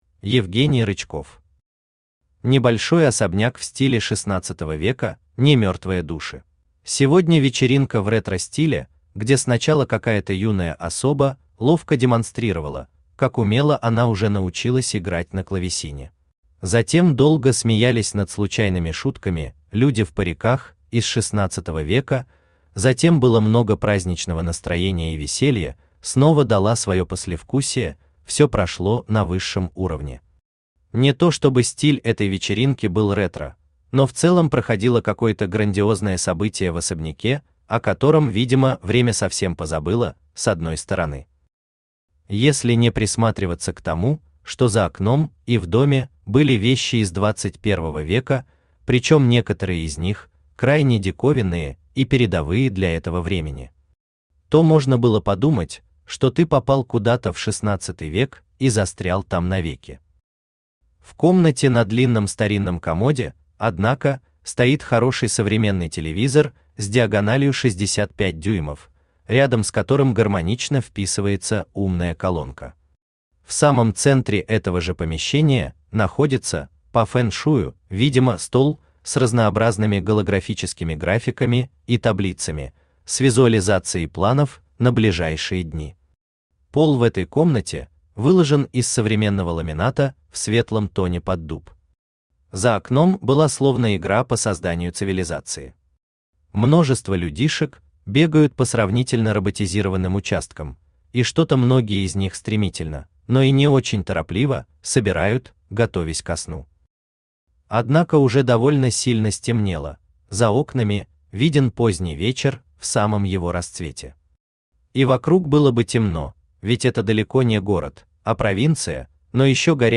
Аудиокнига Небольшой особняк в стиле XVI века | Библиотека аудиокниг
Aудиокнига Небольшой особняк в стиле XVI века Автор Евгений Николаевич Рычков Читает аудиокнигу Авточтец ЛитРес.